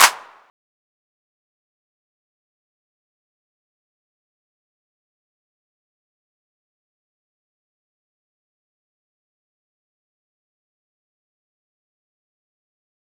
SouthSide Clap (8).wav